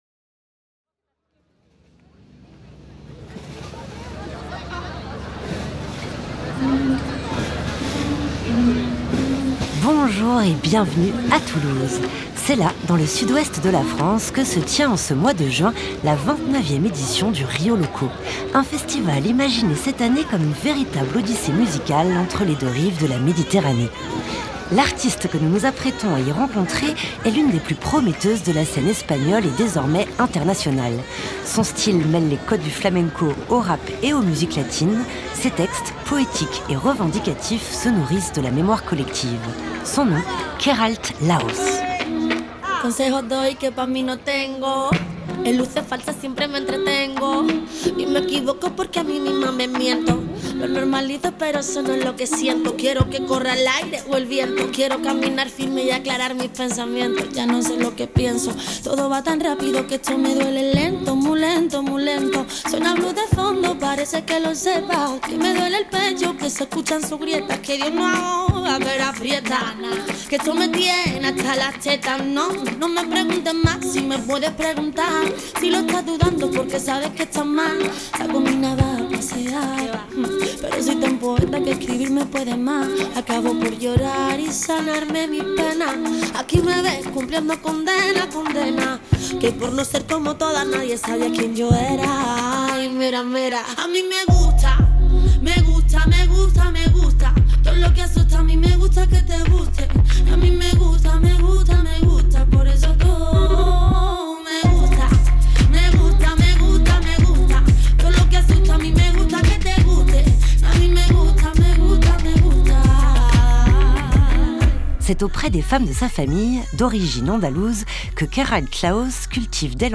Son style mêle les codes du flamenco au rap et aux musiques latines. Sa voix est magnétique et ses textes, poétiques et revendicatifs, se nourrissent de la mémoire collective.
Rencontre au festival Rio Loco (Toulouse) avec l’une des artistes les plus prometteuses de la scène espagnole.